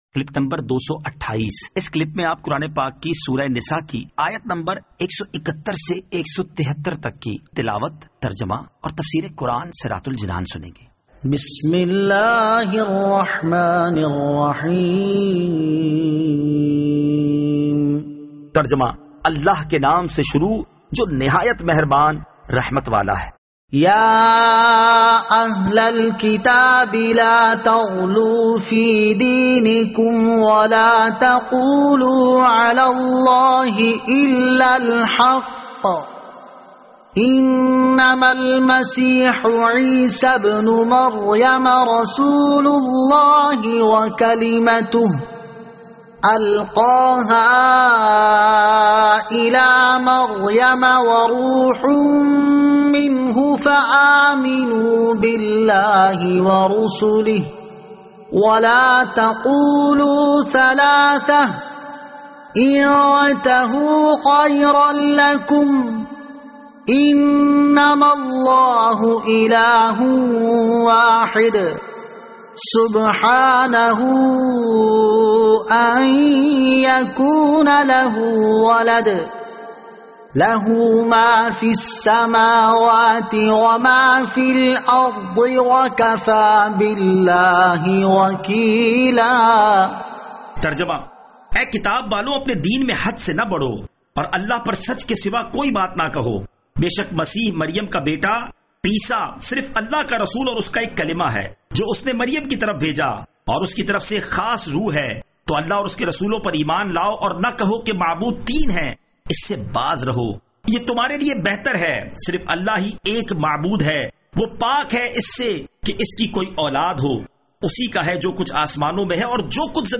Surah An-Nisa Ayat 171 To 173 Tilawat , Tarjama , Tafseer